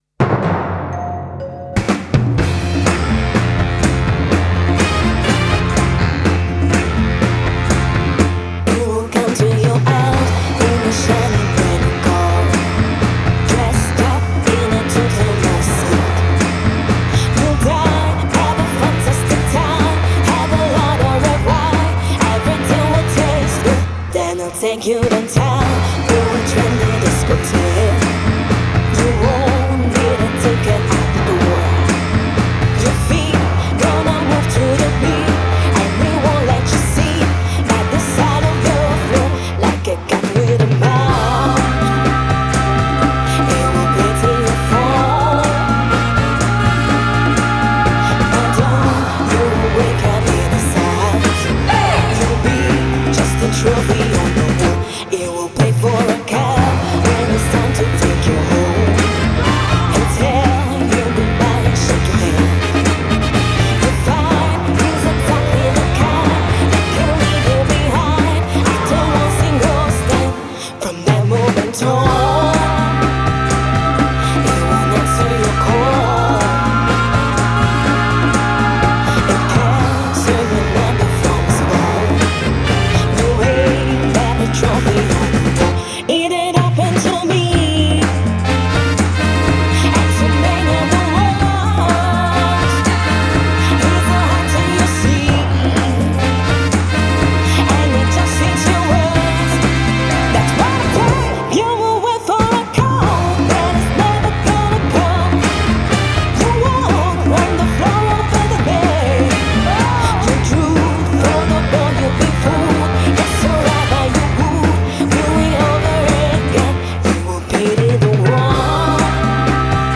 uno stomper inedito in perfetto stile Northern Soul